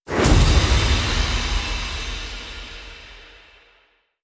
megawin_shot.wav